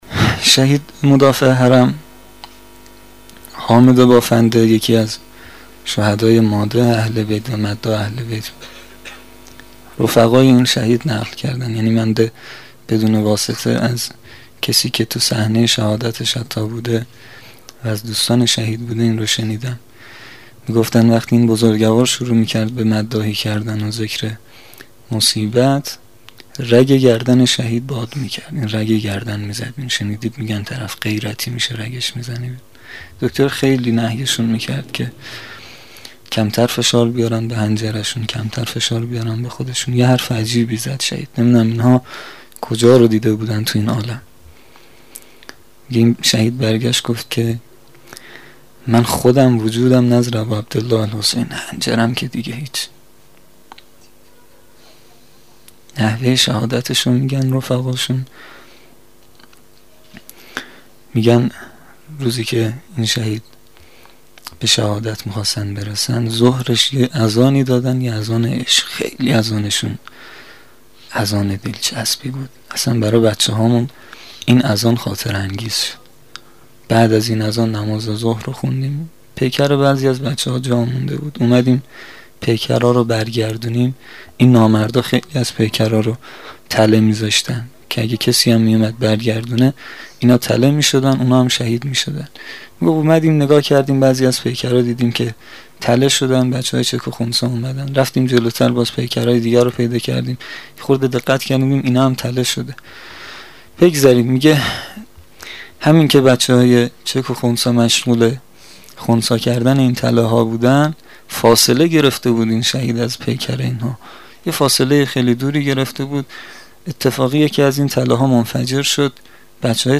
خاطره گویی شهدای مدافع حرم شب ششم محرم الحرام 1396
• هیئت جواد الائمه